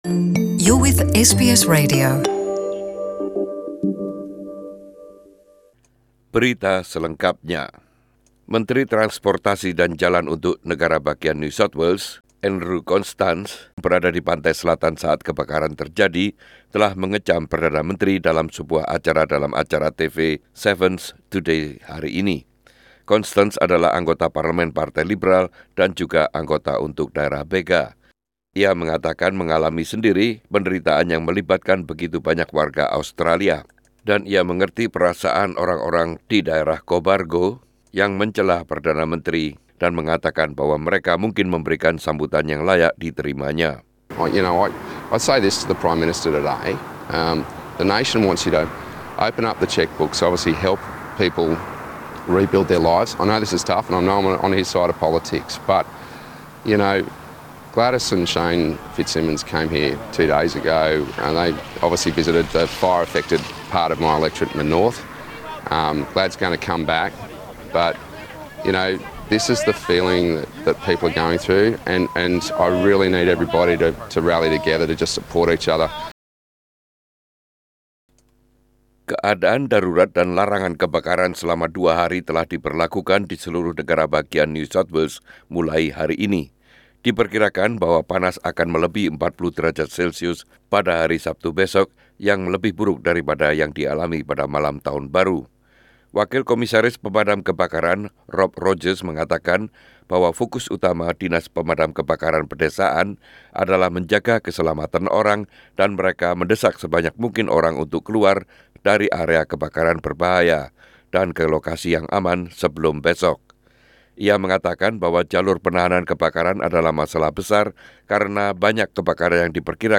SBS Radio News in Indonesia- 03/01/2020